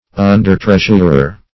Undertreasurer \Un"der*treas`ur*er\, n. An assistant treasurer.